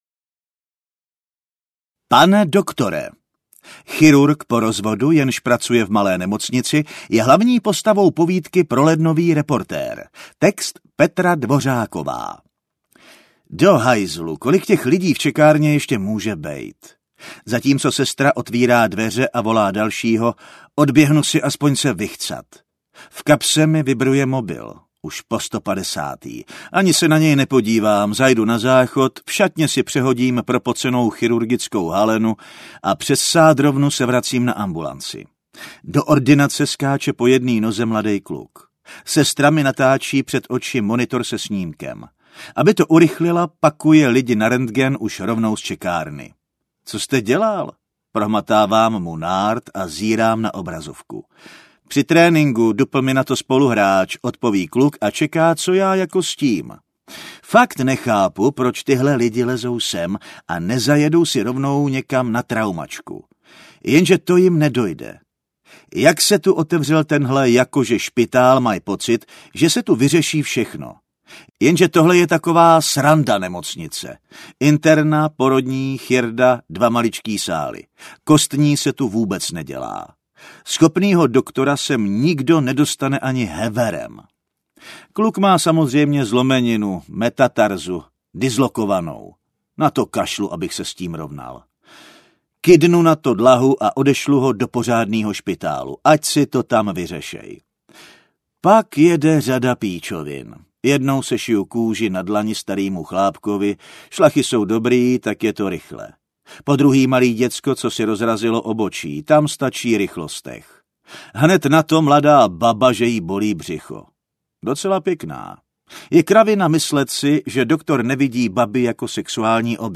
Povídka.